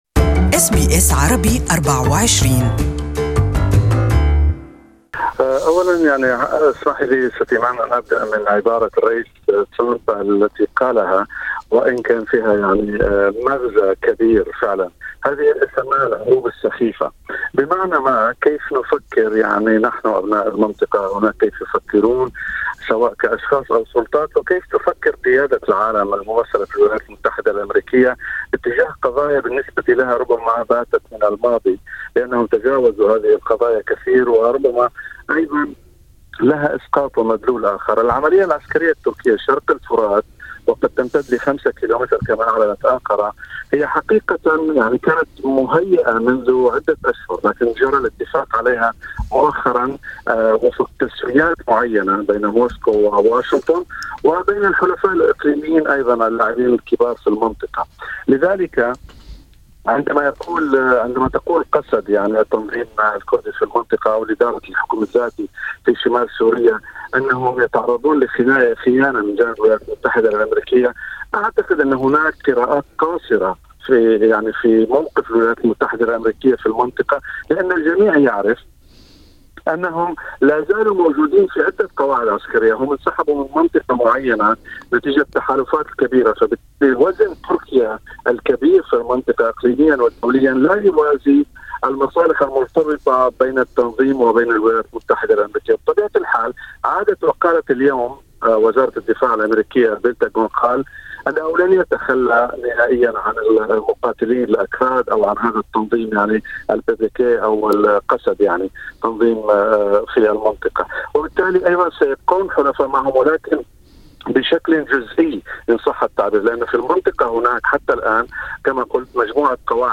This interview is only available in Arabic.